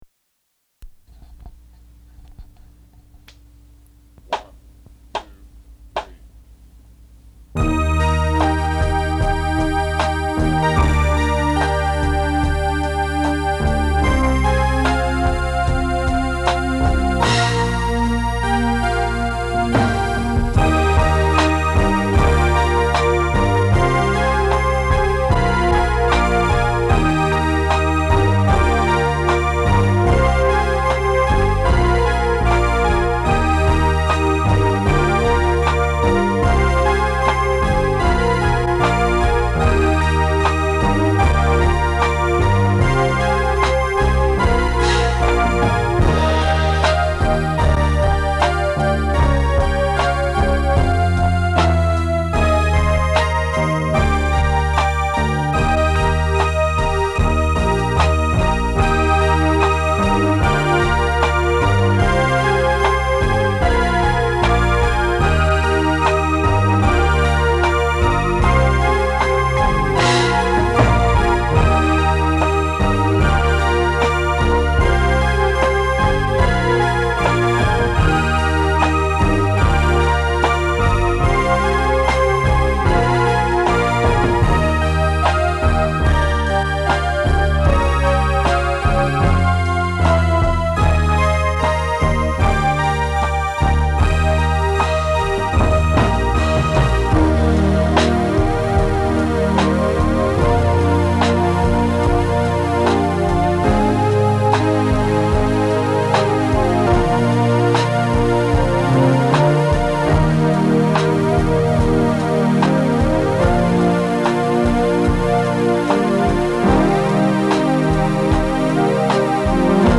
(instrumental)
girlfriend-ballad